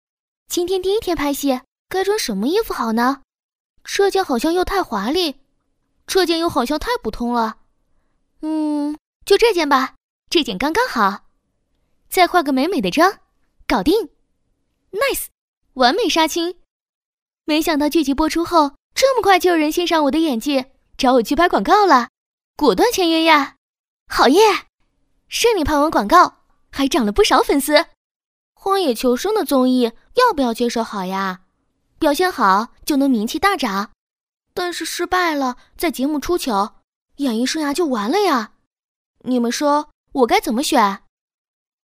女285-抖音广告-【装扮经济公司】
女285-明亮柔和 素人自然